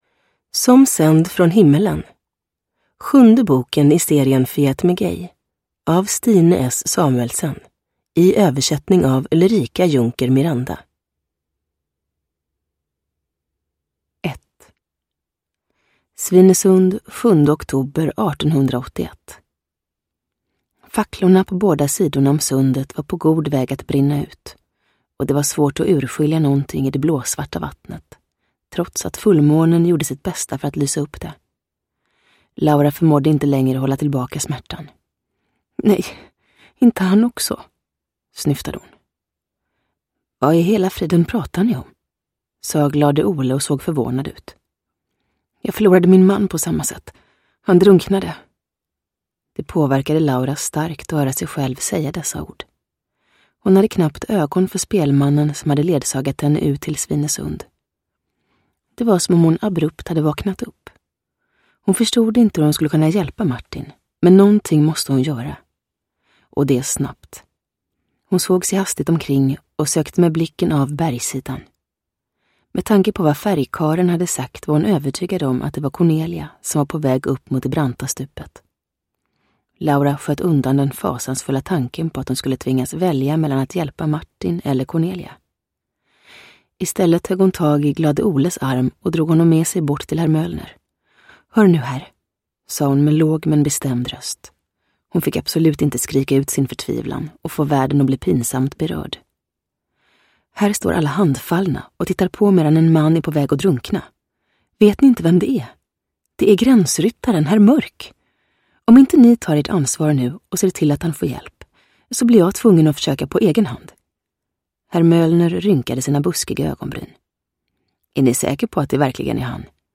Som sänd från himlen – Ljudbok – Laddas ner
Uppläsare: Julia Dufvenius